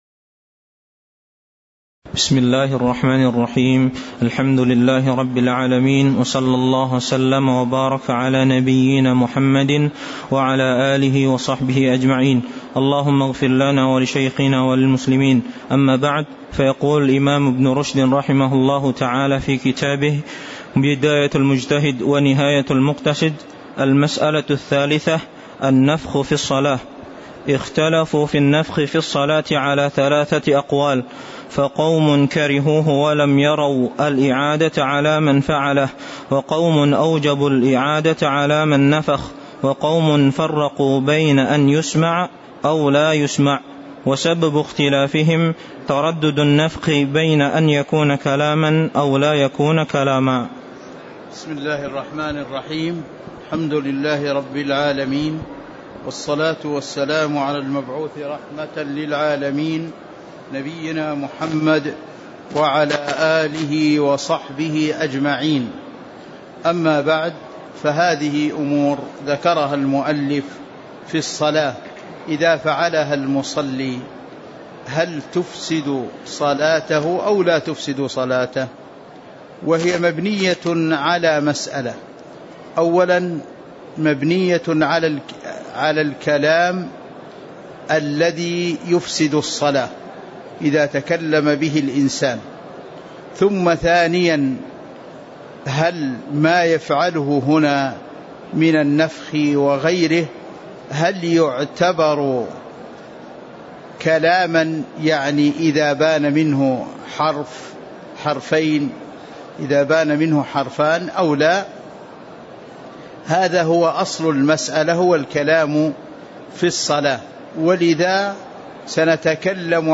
تاريخ النشر ١٥ صفر ١٤٤٤ هـ المكان: المسجد النبوي الشيخ